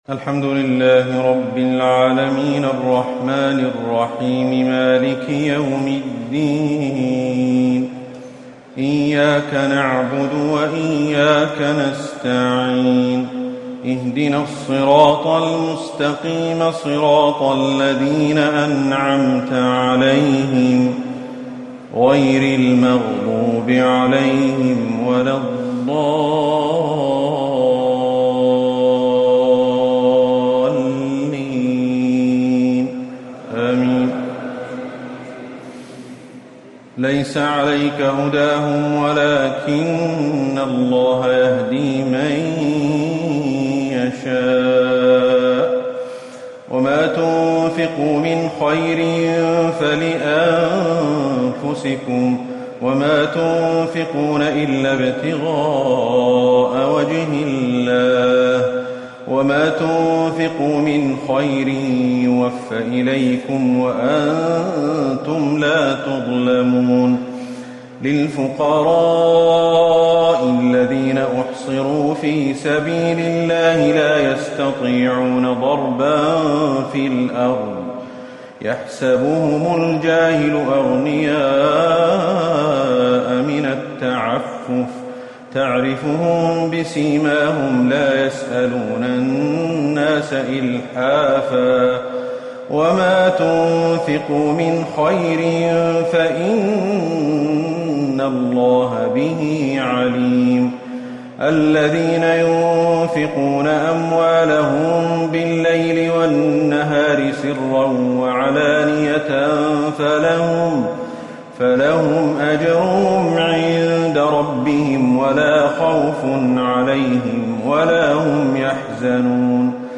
ليلة ٣ رمضان ١٤٤٠هـ من سورة البقرة ٢٧٢-٢٨٦ وال عمران ١-٧٤ > رمضان 1440هـ > التراويح